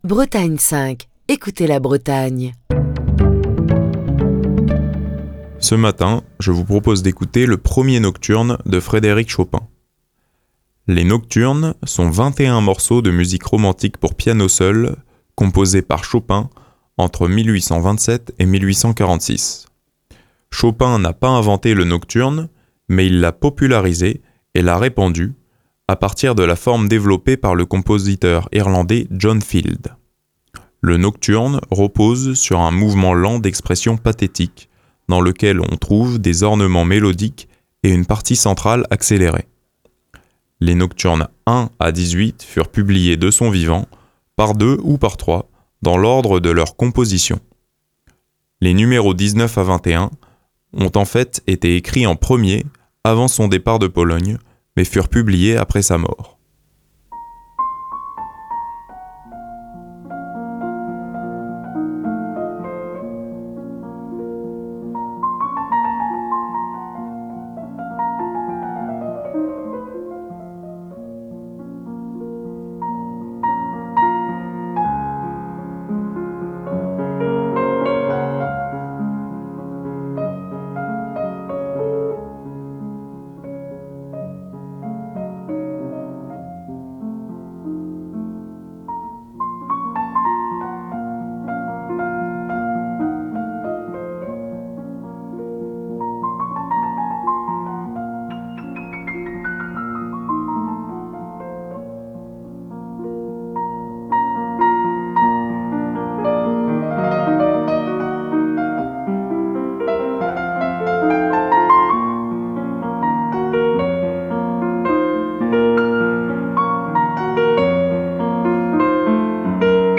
Les Nocturnes sont vingt-et-un morceaux de musique romantique pour piano seul, composés par Chopin entre 1827 et 1846.
Le nocturne repose sur un mouvement lent d'expression pathétique, dans lequel on trouve des ornements mélodiques et une partie centrale accélérée.
Nous concluons cette semaine avec le premier Nocturne de Frédéric Chopin, interprété par l’immense Nelson Freire. Le pianiste brésilien, né en 1944 , nous a quitté en 2021.